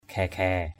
/ɡʱɛ:-ɡʱɛ:/ (t.) hề hề. (laughing sound). ngap suail blaoh daok klao ghaiy-ghaiy ZP =s&L _d<K _k*< =GY-=GY làm sai mà còn cười hề hề.
ghaiy-ghaiy.mp3